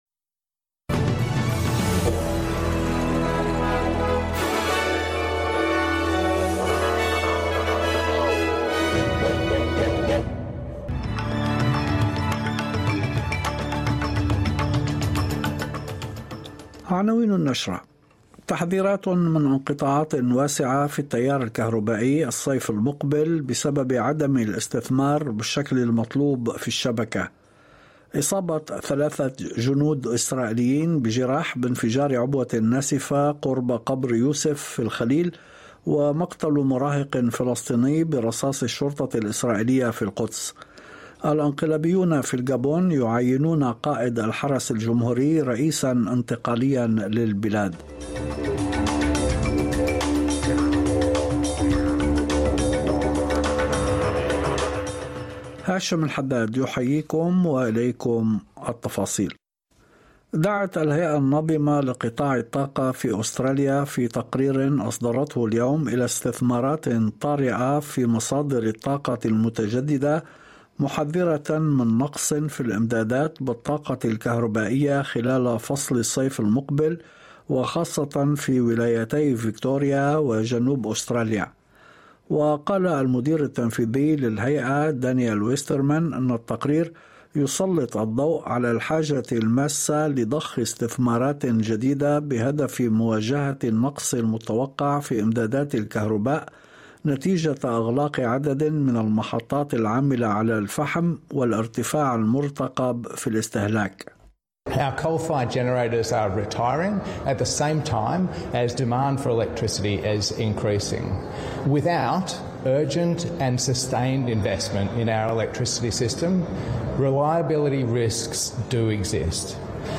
نشرة أخبار المساء 31/8/2023
يمكنكم الاستماع الى النشرة الاخبارية كاملة بالضغط على التسجيل الصوتي أعلاه.